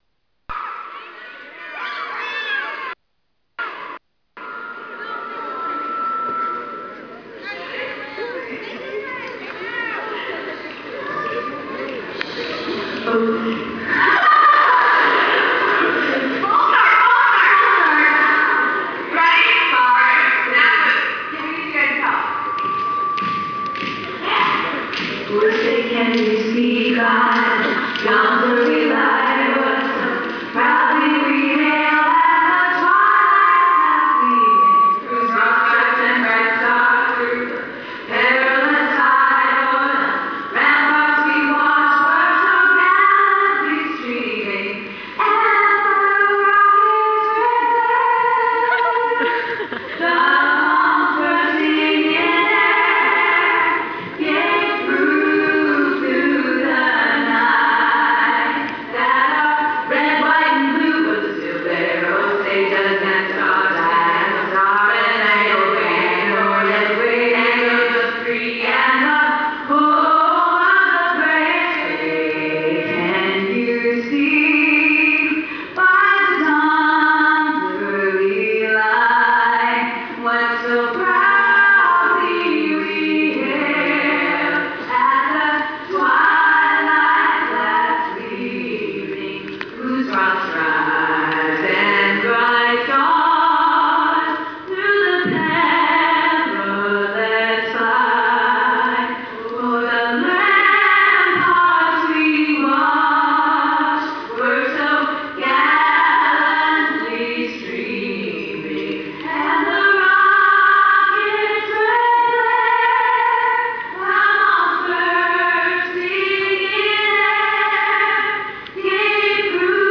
Hilarie, Bethany et Elisabeth Harnois (Shelly dans la saison 4) ont interprété l'hymne américain Télécharger un enregistrement amateur (qualité moyenne).
NationalAnthem.wav